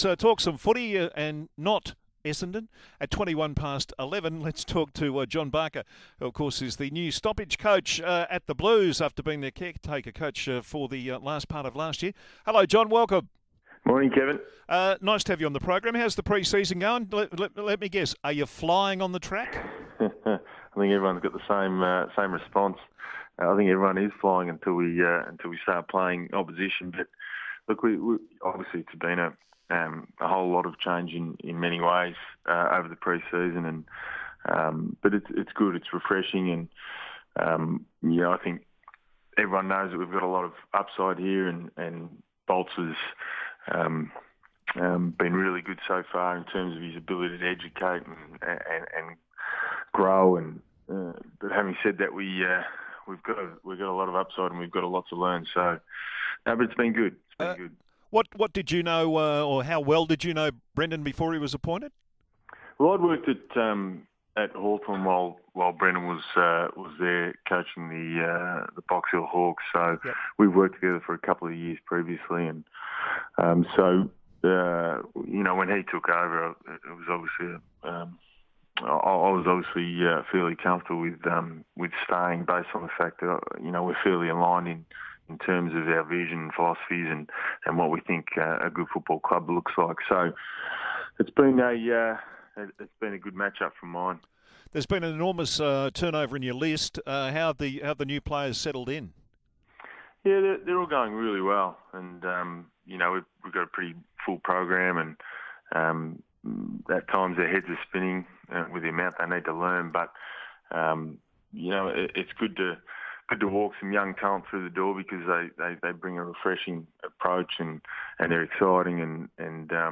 speaks to SEN 1116